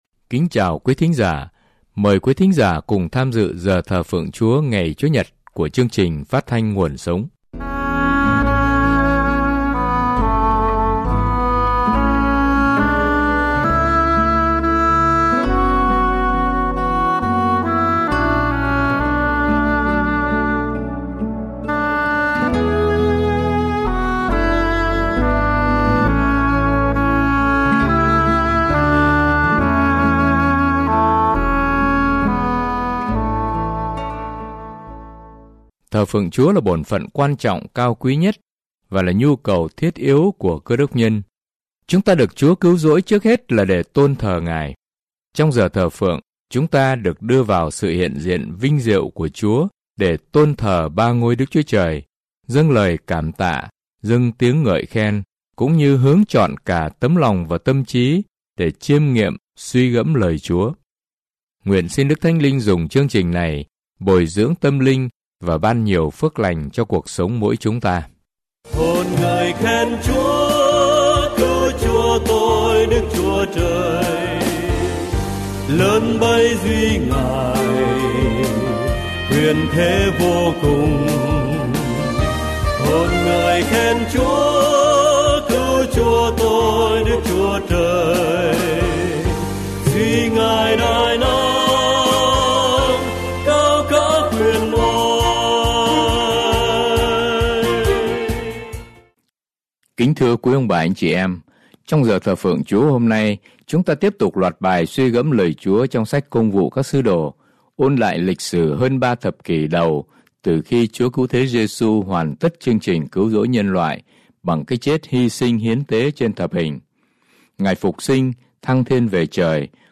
Chương trình: Thờ Phượng Giảng Luận